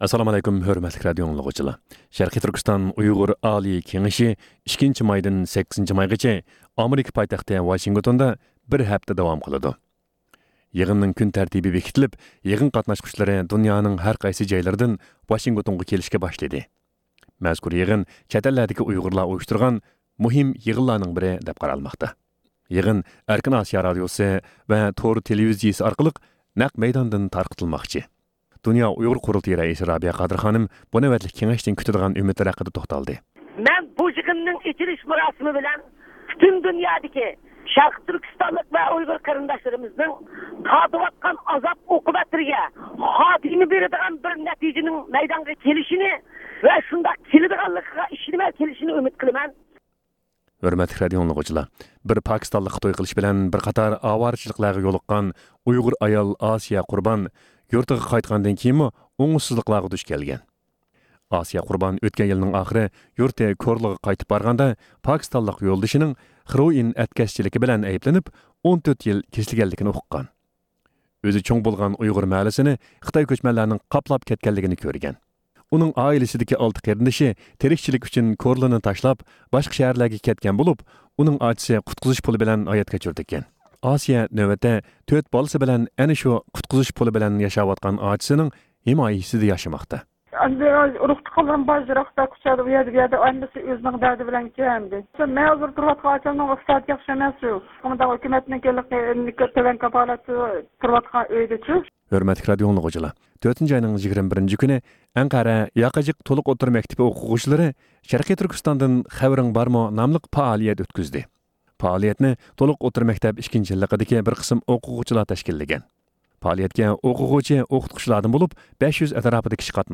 ھەپتىلىك خەۋەرلەر (23-ئاپرېلدىن 29-ئاپرېلغىچە) – ئۇيغۇر مىللى ھەركىتى